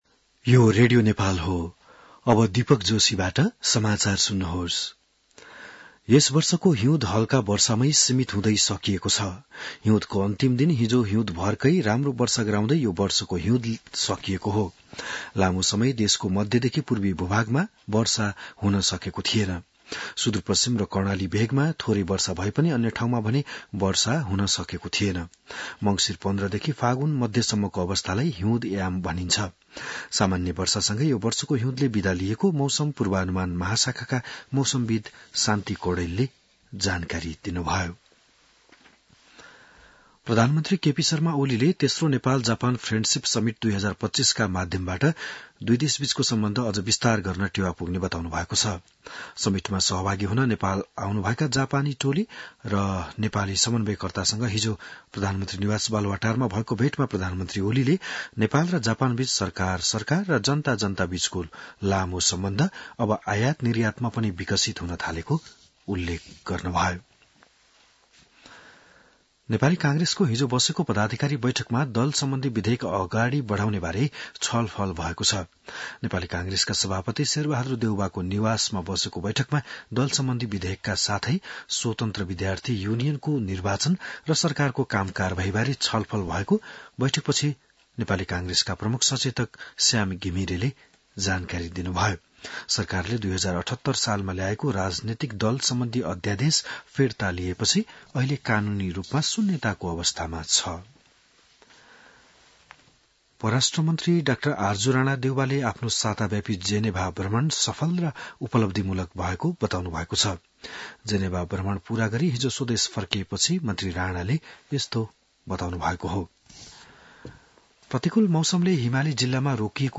बिहान १० बजेको नेपाली समाचार : १८ फागुन , २०८१